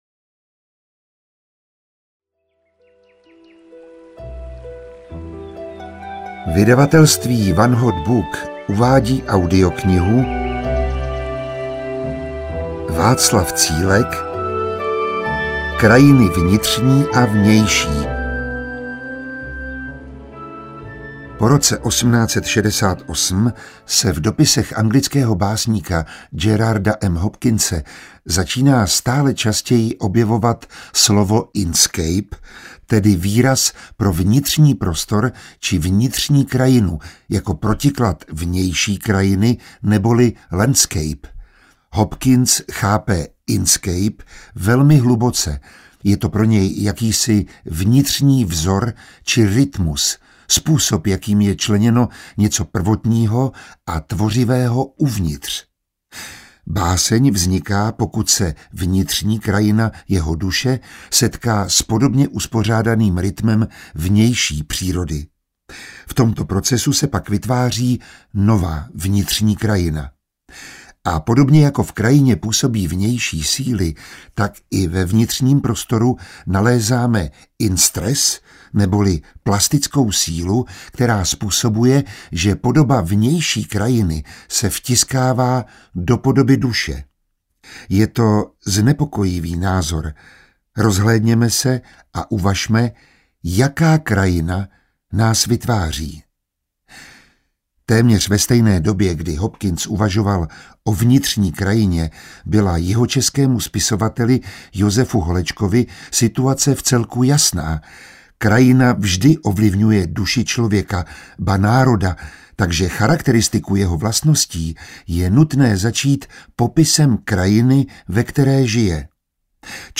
Krajiny vnitřní a vnější audiokniha
Ukázka z knihy